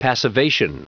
Prononciation du mot passivation en anglais (fichier audio)